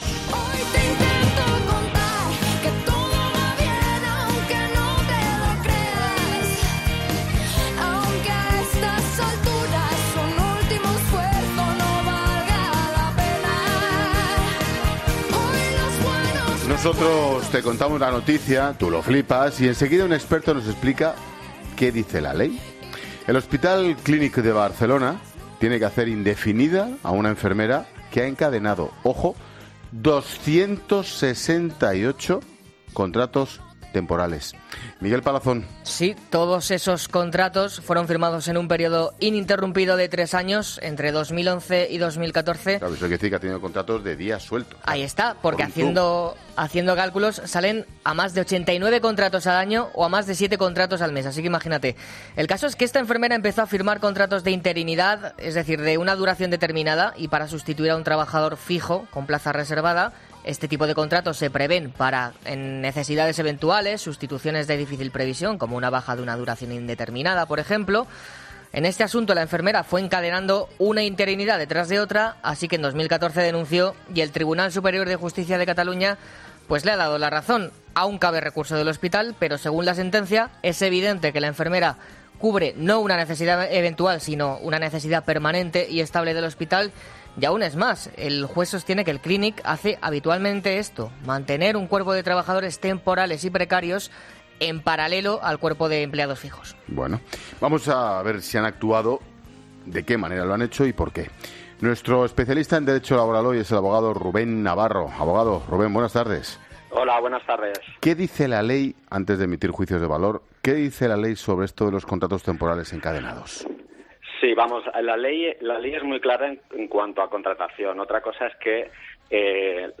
abogado experto en Derecho Laboral